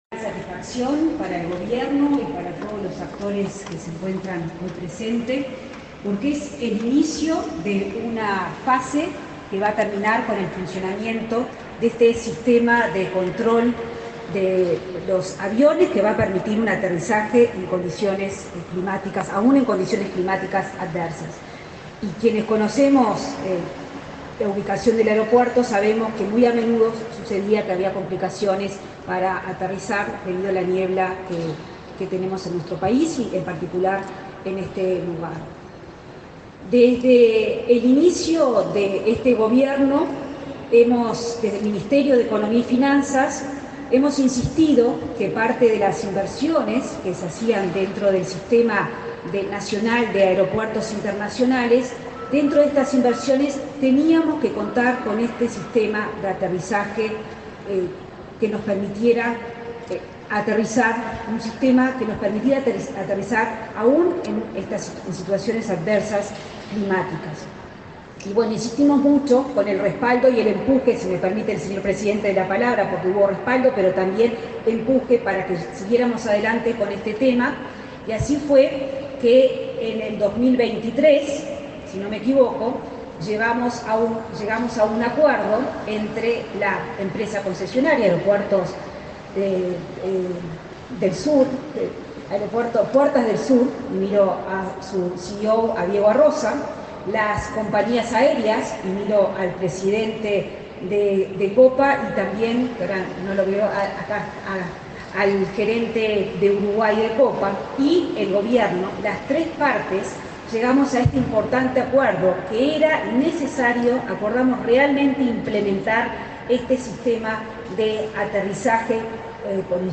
Palabras de la ministra de Economía, Azucena Arbeleche
Palabras de la ministra de Economía, Azucena Arbeleche 10/10/2024 Compartir Facebook X Copiar enlace WhatsApp LinkedIn La ministra de Economía, Azucena Arbeleche, se expresó durante la presentación del nuevo sistema de aterrizaje instrumental en el aeropuerto de Carrasco.